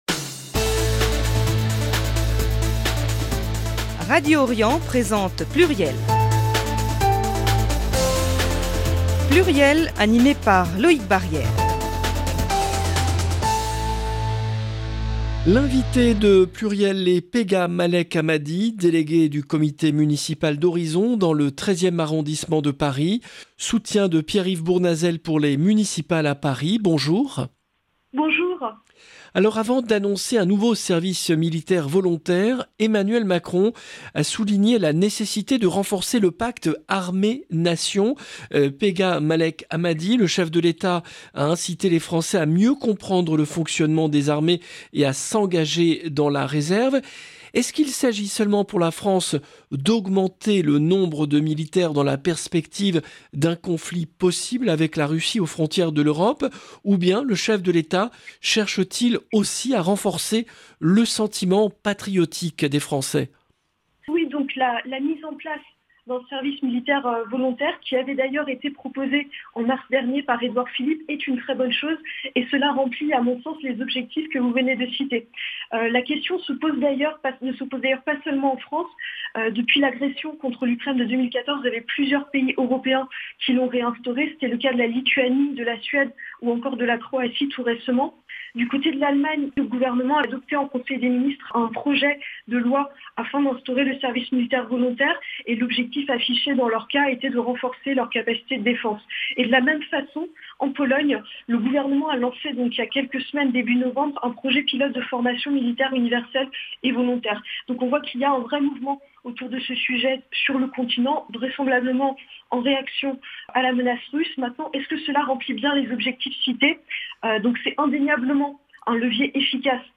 L’entretien s’est conclu par ses propositions pour la campagne municipale à Paris, notamment en matière de propreté et de sécurité. 0:00 15 min